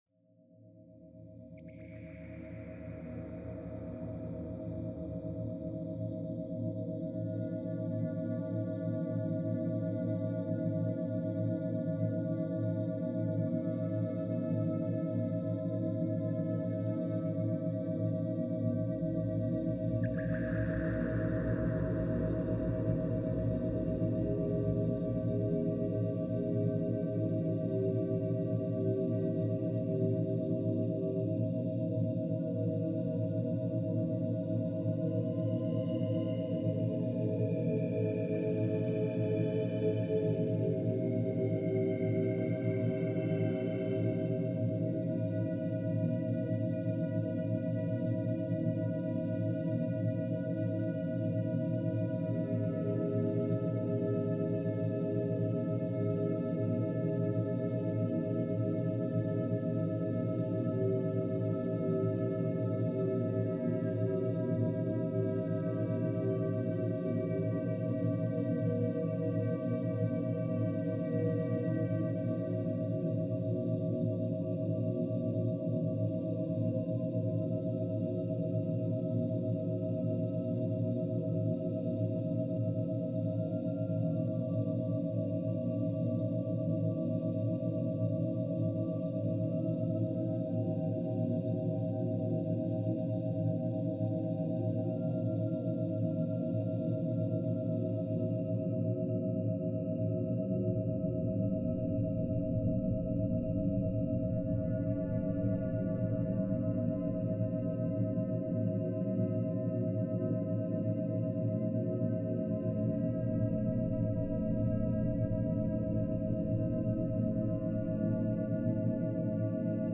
Total Healing Meditation – Binaural + Monaural for Body and Mind